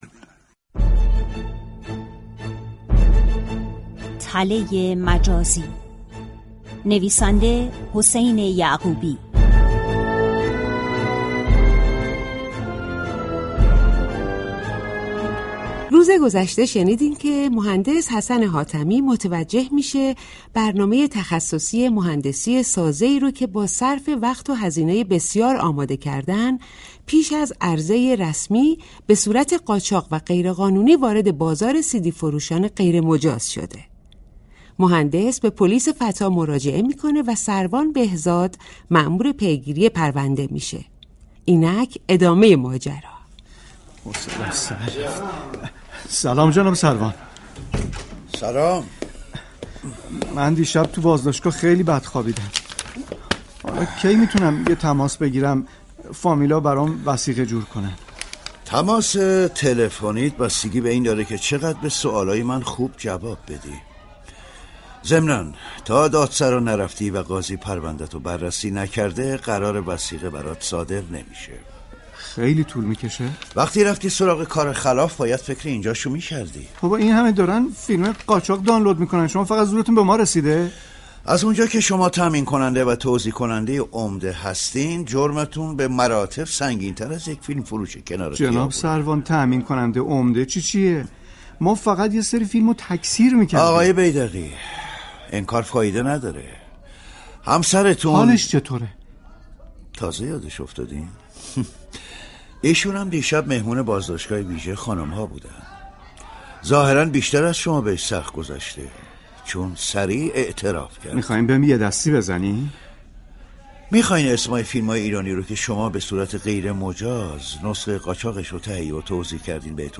26 بهمن ماه ، شنونده نمایش رادیویی